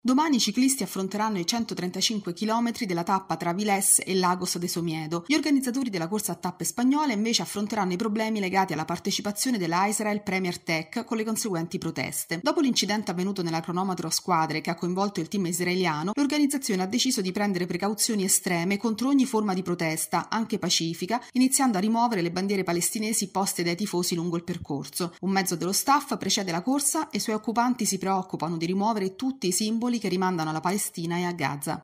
Sport